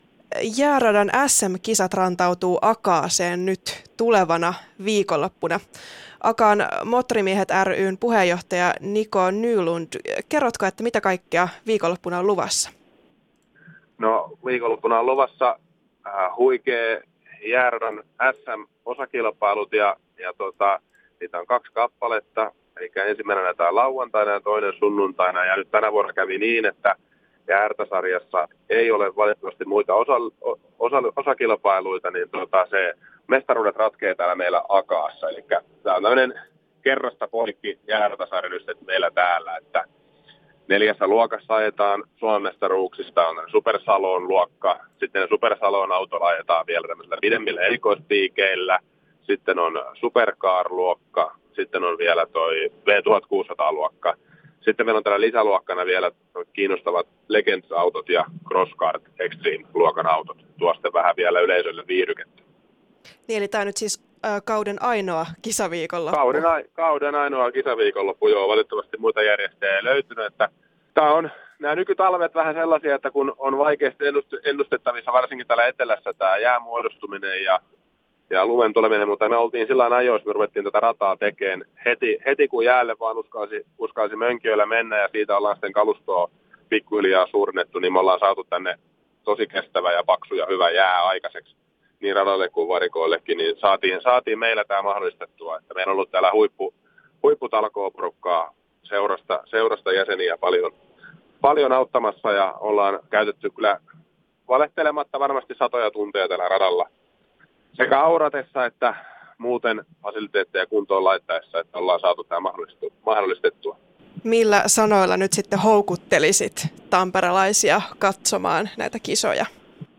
Päivän haastattelu